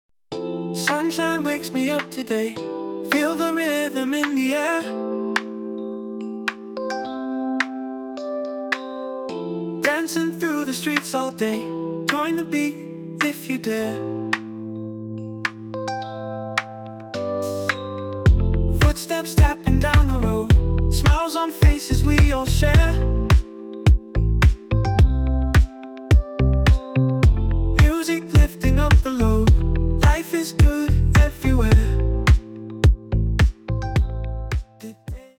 Short version of the song, full version after purchase.
An incredible R&B song, creative and inspiring.